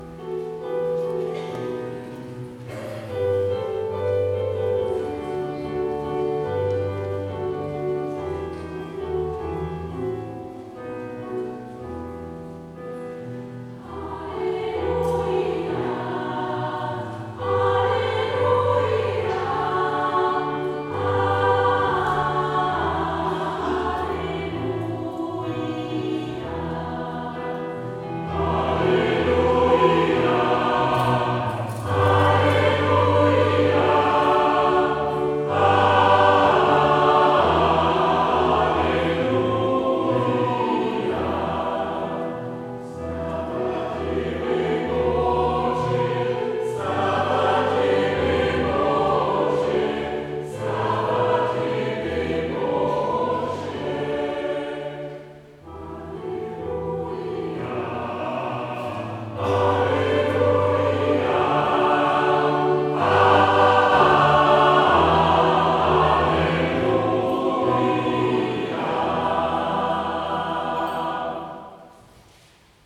Heropening Sint-Pieterskerk Rotselaar